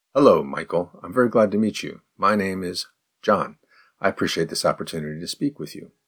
01_advanced_response_fast.mp3